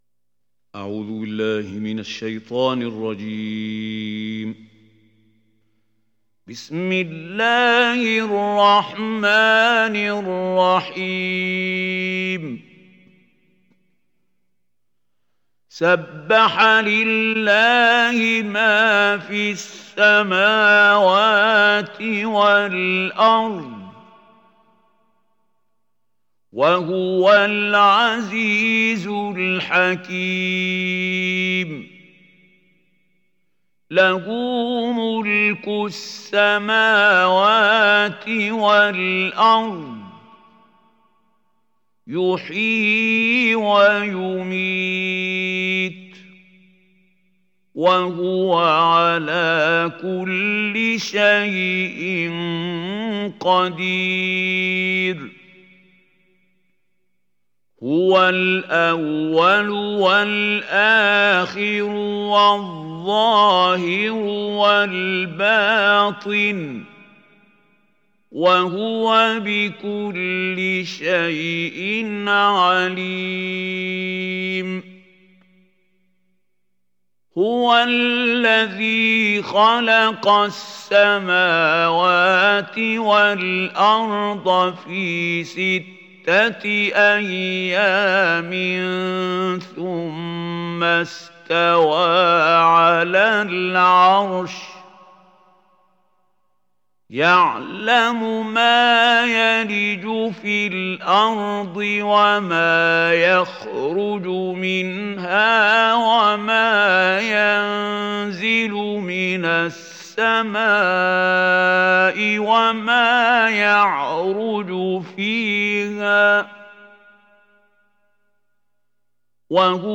تحميل سورة الحديد mp3 بصوت محمود خليل الحصري برواية حفص عن عاصم, تحميل استماع القرآن الكريم على الجوال mp3 كاملا بروابط مباشرة وسريعة